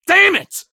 gun_jam_4.ogg